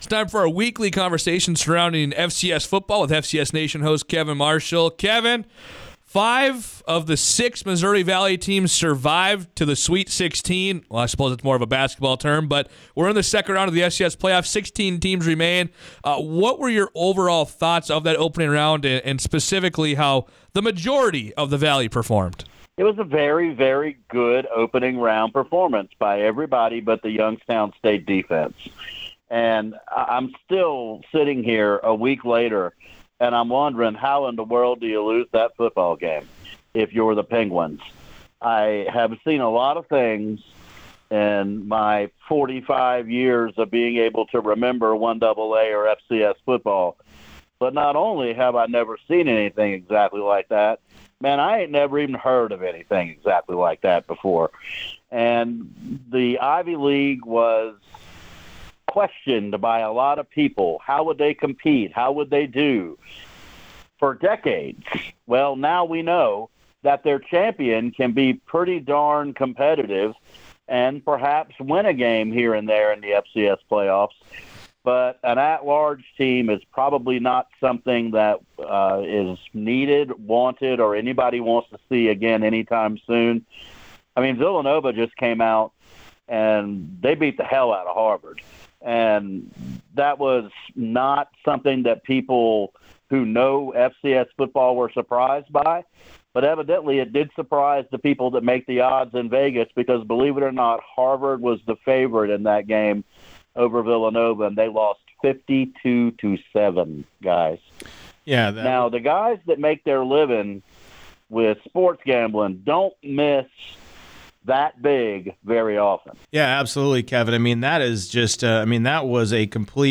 for their weekly conversation surrounding the subdivision. They recapped the first round of the playoff and previewed the second round of the playoff.